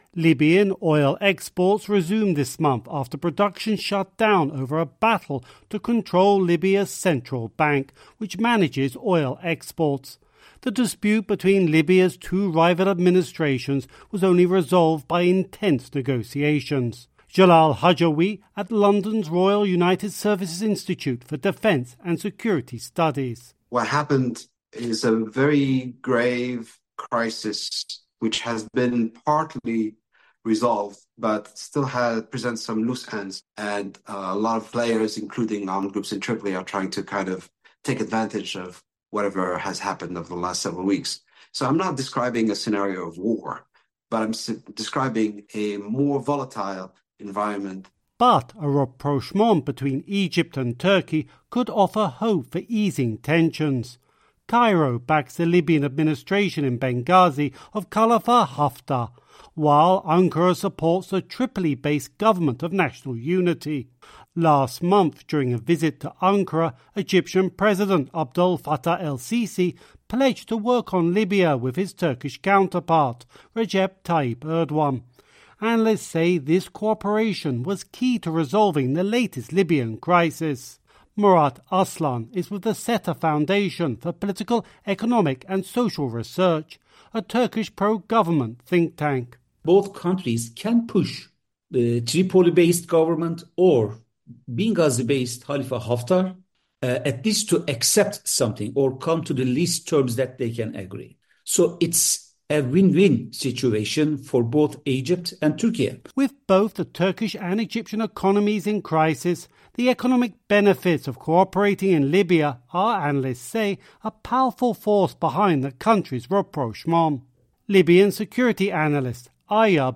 reports from Istanbul